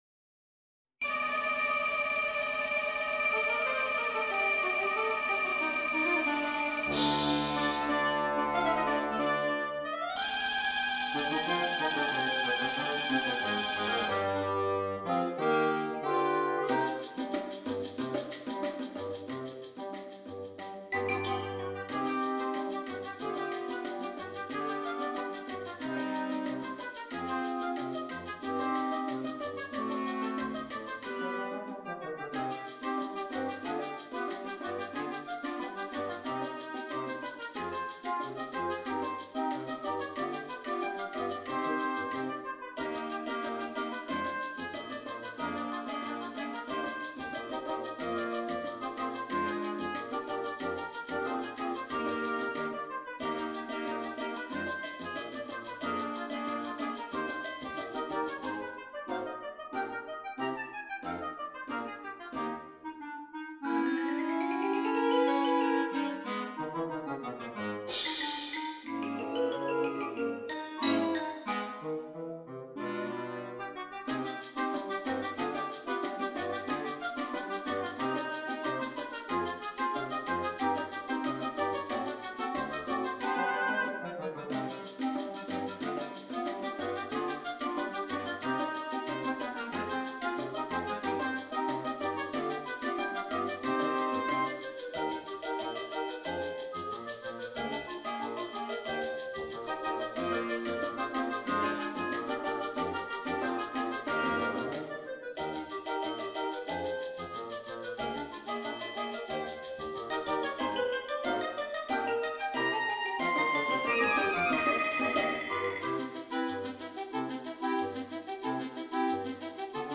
Interchangeable Woodwind Ensemble
PART 1 - Flute, Oboe, Clarinet
PART 2 - Flute, Clarinet, Alto Saxophone
PART 5 - Baritone Saxophone, Bass Clarinet, Bassoon
Xylophone / Bells
Bongos